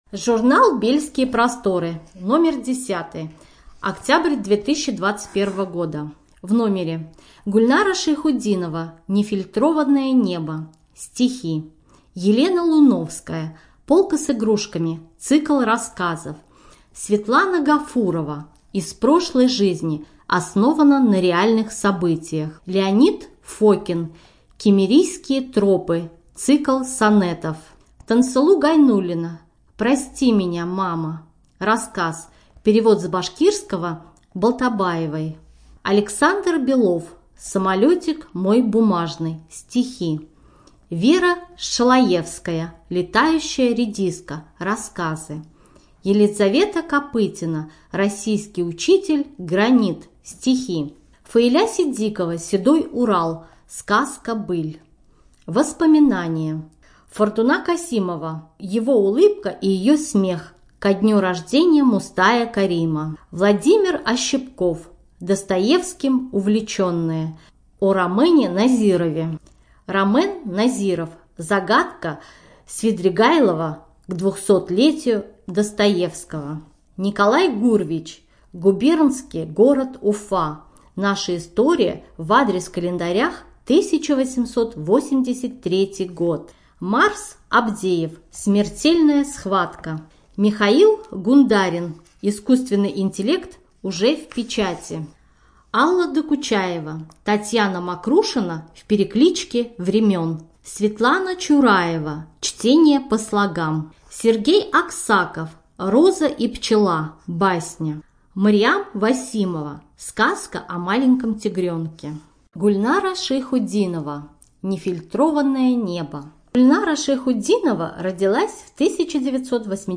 Студия звукозаписиБашкирская республиканская специальная библиотека для слепых